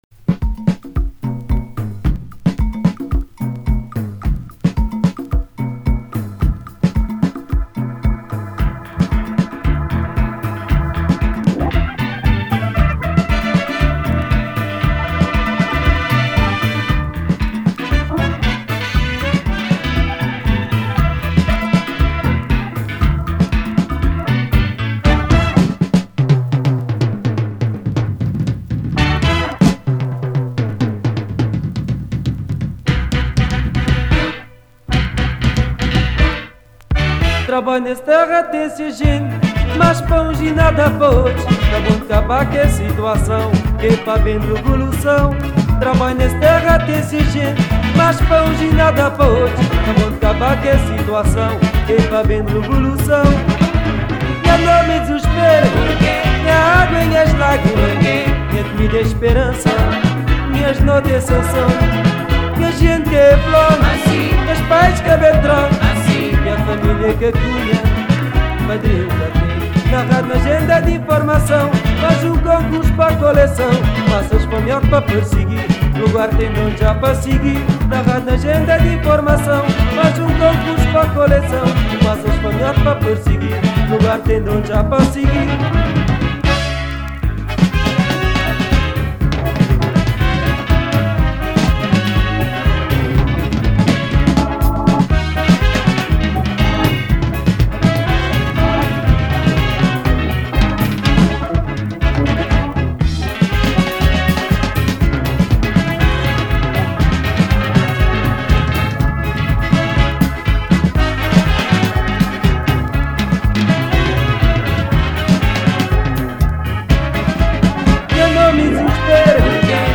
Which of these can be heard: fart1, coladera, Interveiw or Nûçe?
coladera